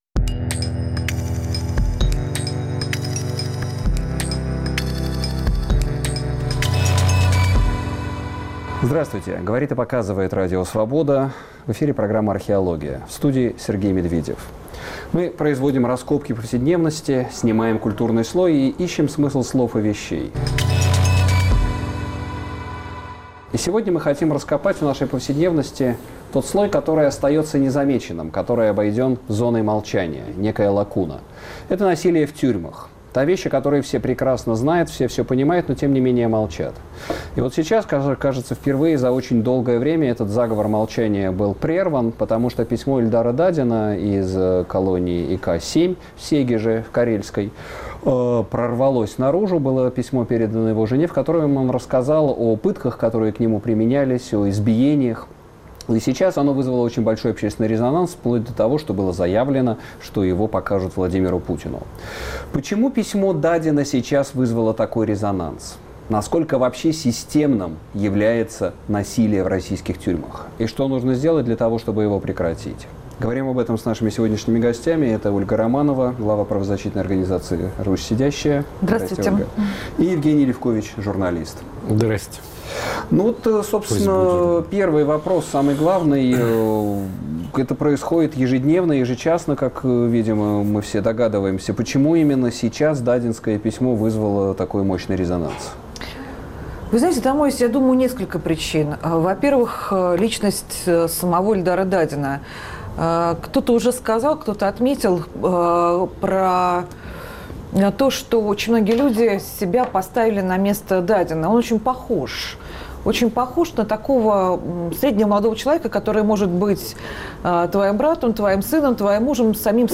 Гость в студии